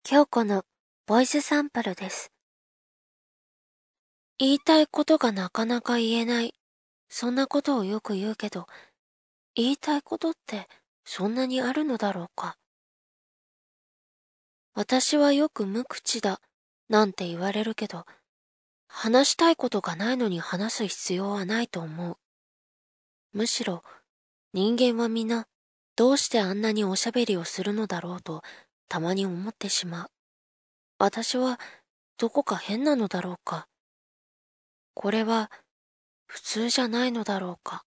コメント 　サンプルはファイルサイズを軽くするために少し音質が悪くなってますので、実際はもう少しクリアだと思います。
無口系女の子。モノローグ的な。